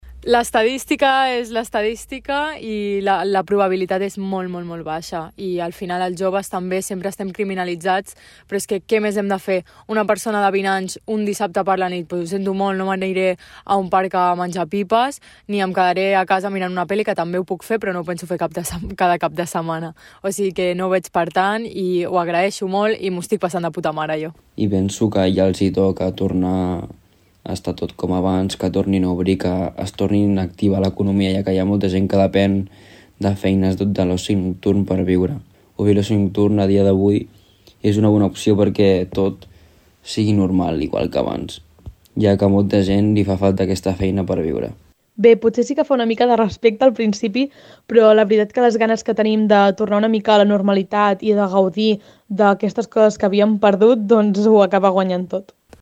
En declaracions a Ràdio Tordera ens explicaven que ja era hora que s’obrís el sector i que esperaven amb moltes ganes aquesta reobertura.